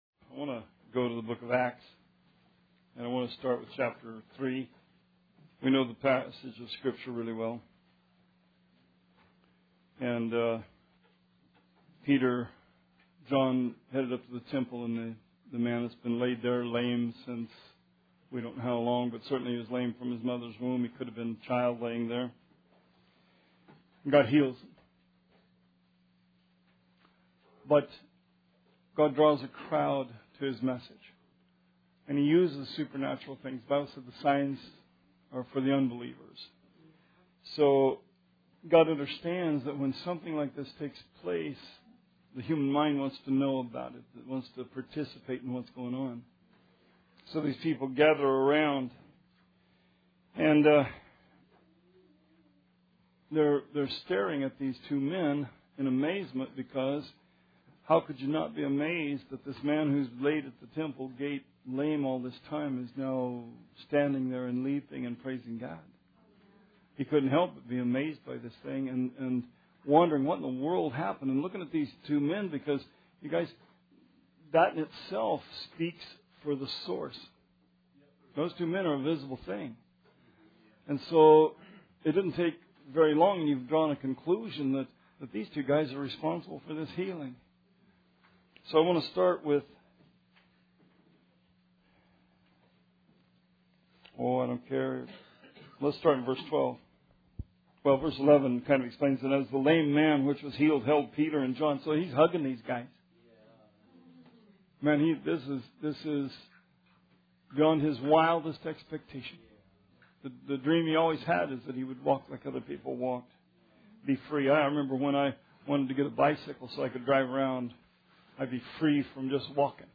Sermon 4/23/17